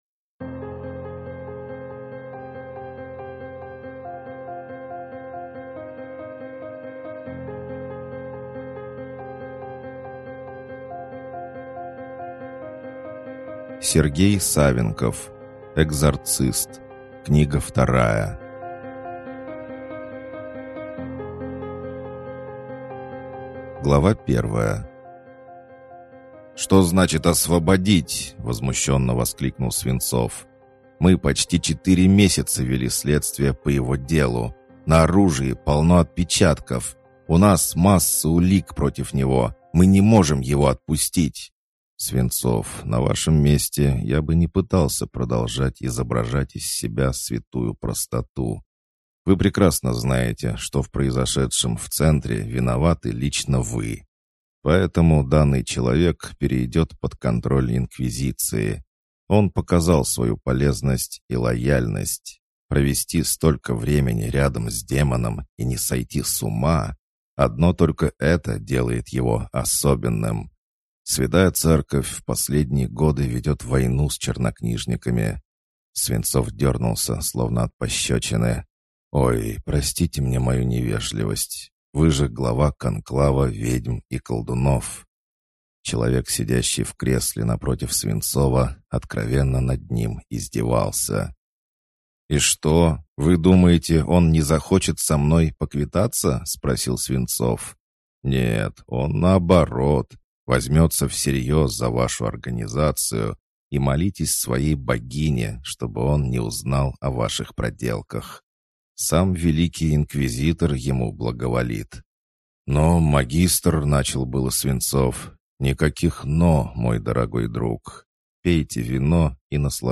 Аудиокнига Экзорцист. Книга 2 | Библиотека аудиокниг